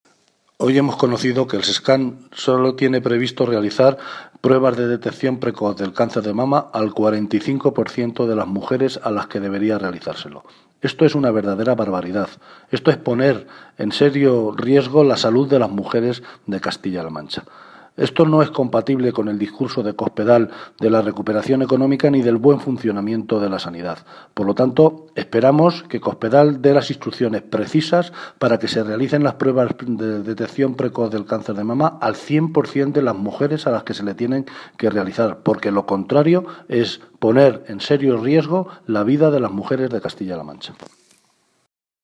El portavoz del Grupo Socialista en las Cortes de Castilla-La Mancha, José Luis Martínez Guijarro, pidió hoy a la presidenta de Castilla-La Mancha, María Dolores de Cospedal, dé “las instrucciones precisas” para que el programa de detección precoz del cáncer de mama llegue al 100% de la población diana de nuestra región.
Cortes de audio de la rueda de prensa